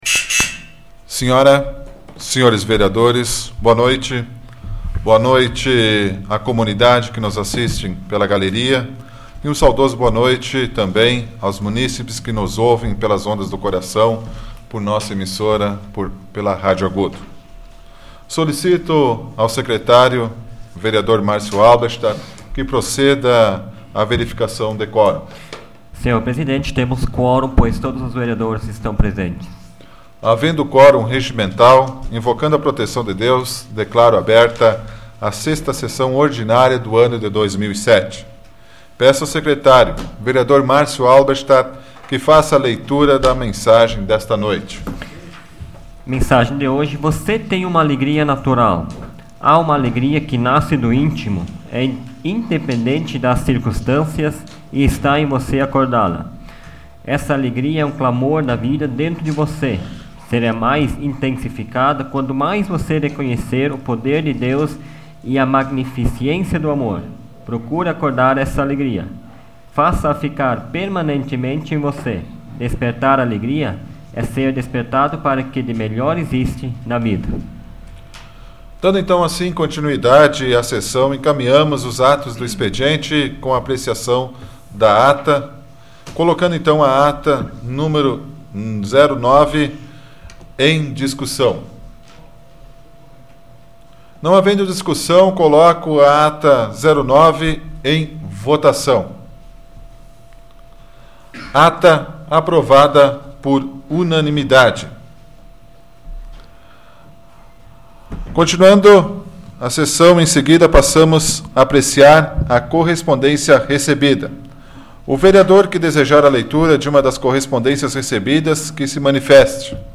Áudio da 80ª Sessão Plenária Ordinária da 12ª Legislatura, de 09 de abril de 2007